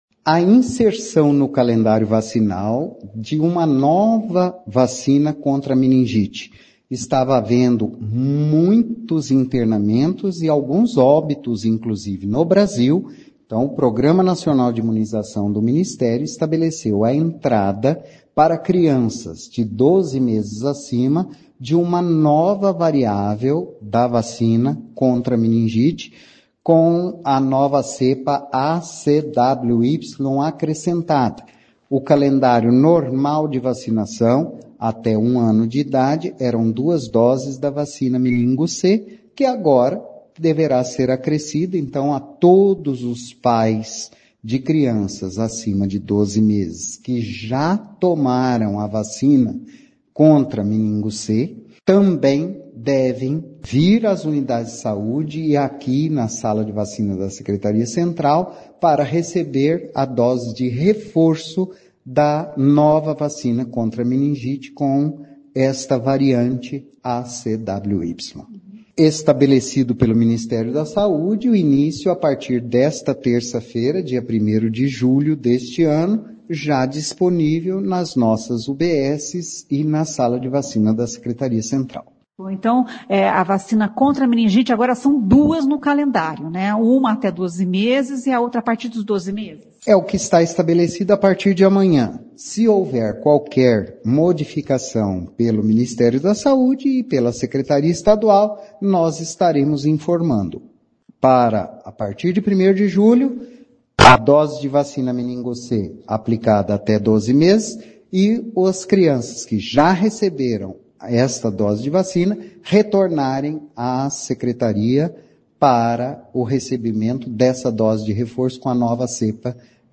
O secretário de Saúde Antônio Carlos Nardi fala sobre a BCG e a inserção da nova vacina contra a meningite no calendário vacinal: